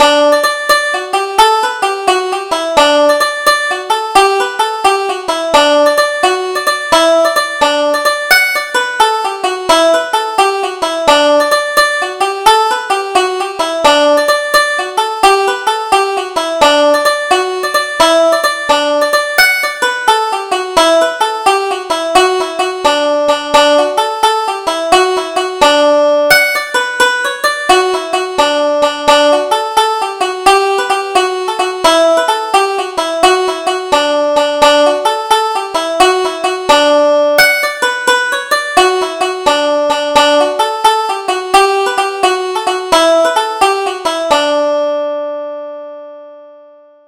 Double Jig: The Jolly Joker